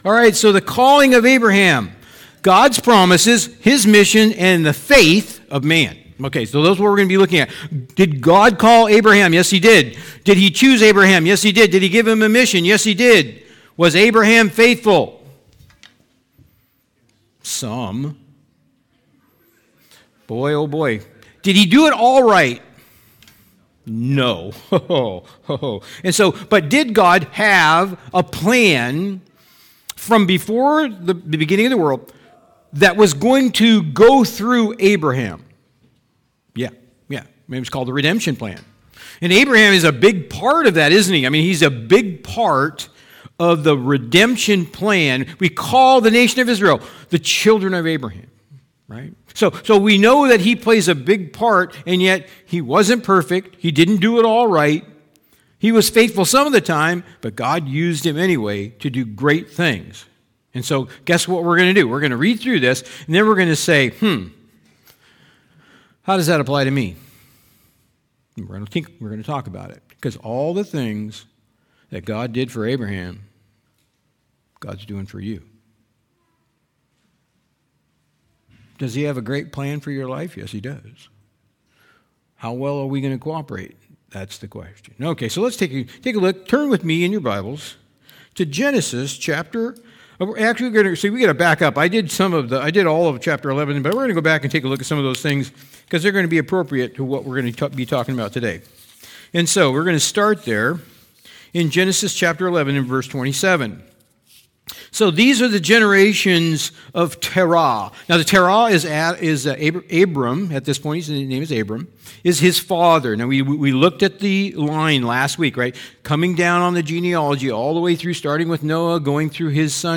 Sermons | Machias Community Church